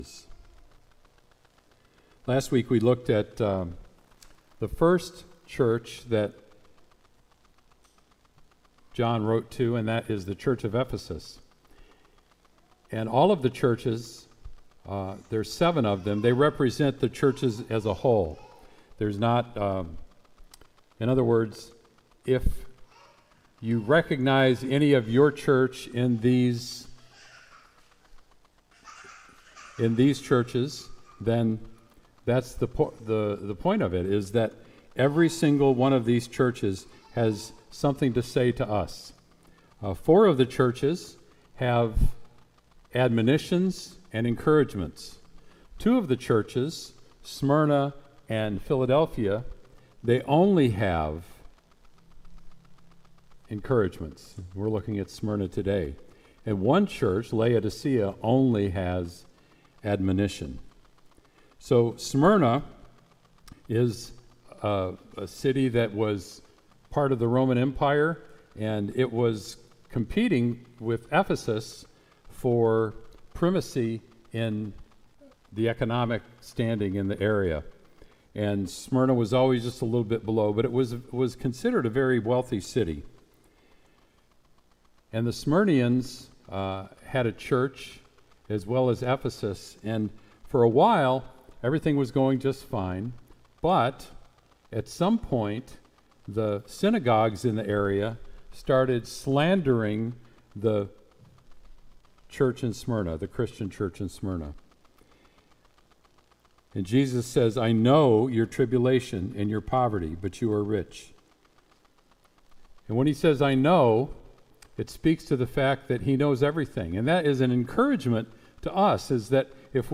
Sermon “Faithful unto Death”